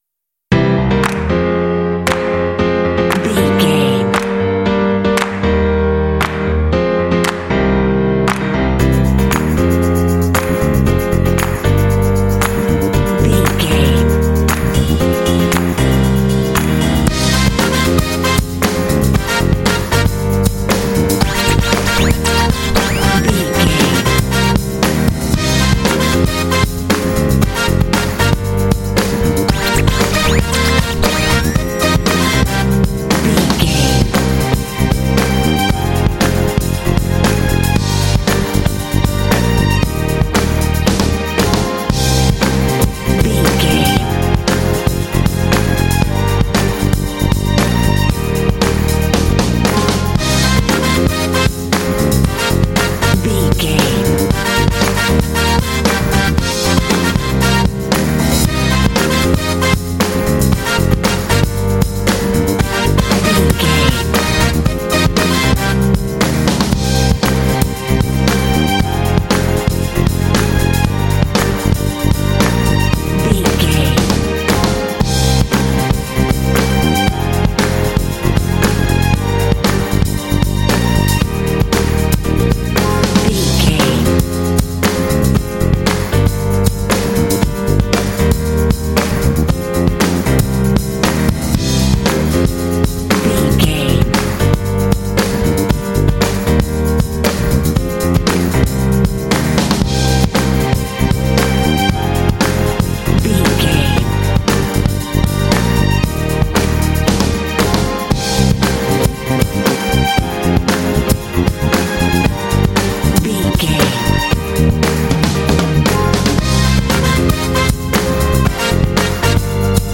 groovy motown track
Aeolian/Minor
funky
happy
bouncy
piano
drums
bass guitar
brass
synthesiser
strings
Funk